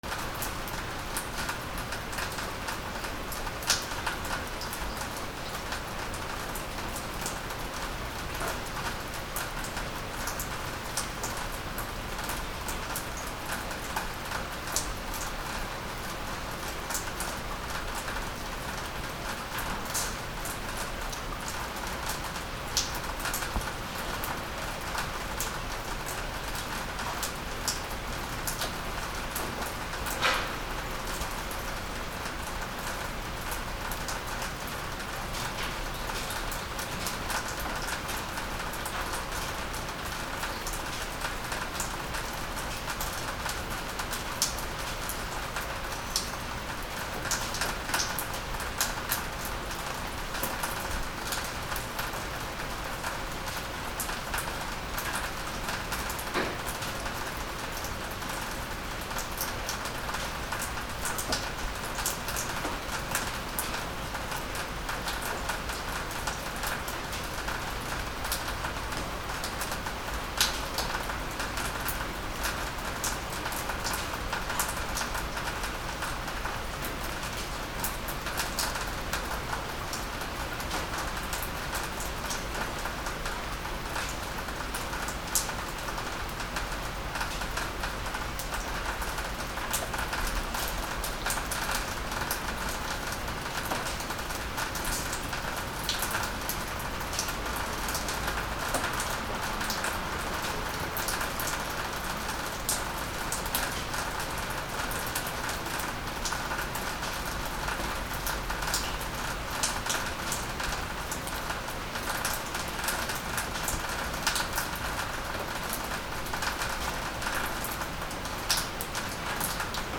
雨だれ
/ A｜環境音(天候) / A-25 ｜雨だれ
アンビエンス ビチャビチャ